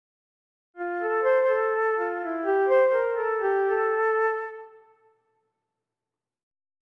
256 - L18P23 - lecture chantée - complet